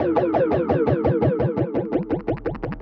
Index of /musicradar/rhythmic-inspiration-samples/85bpm
RI_DelayStack_85-11.wav